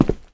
step6.wav